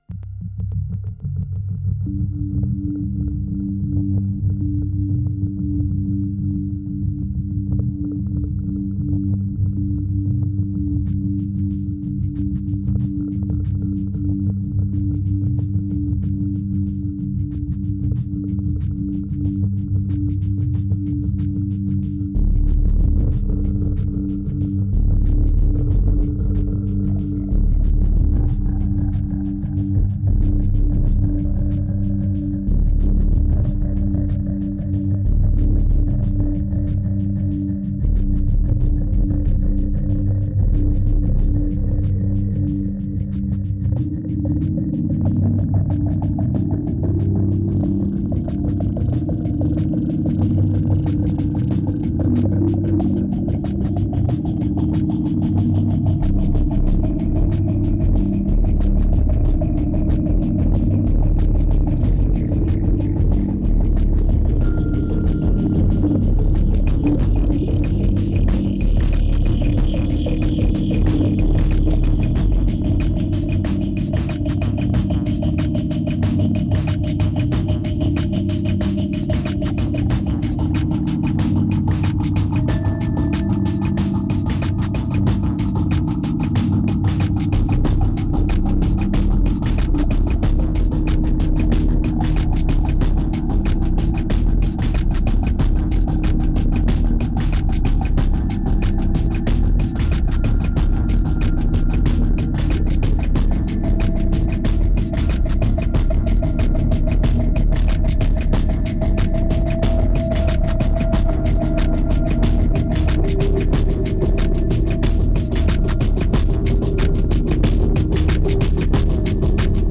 2539📈 - -9%🤔 - 93BPM🔊 - 2011-01-23📅 - -309🌟